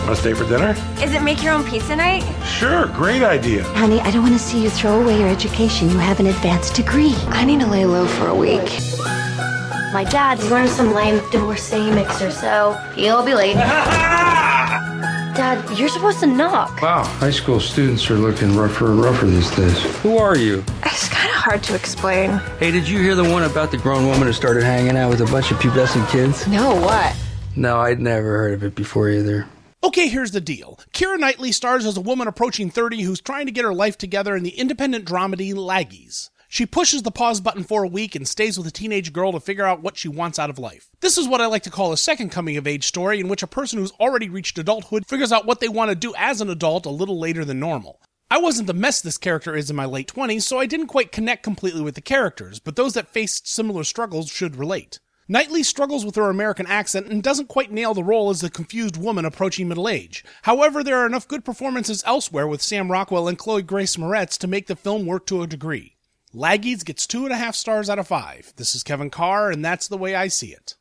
‘Laggies’ Movie Review